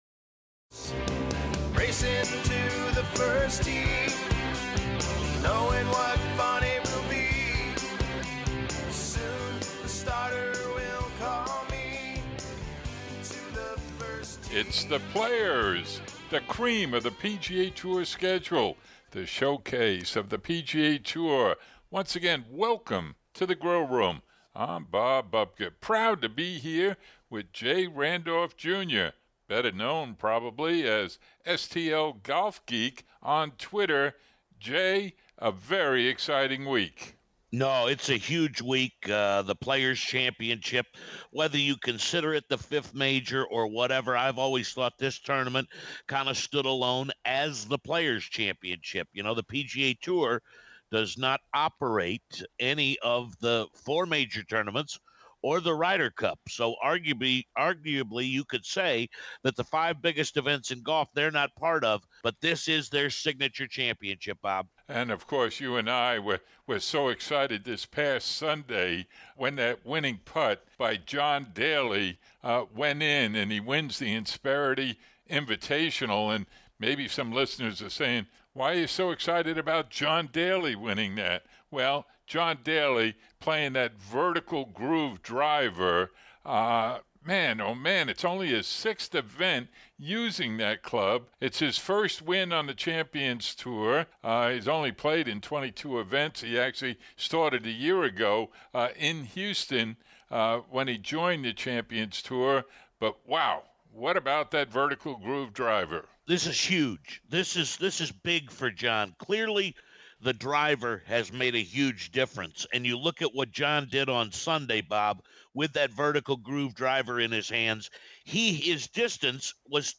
There's lots of in-depth discussions this week with audio from Daly, Jason Day, and Rory McIlroy.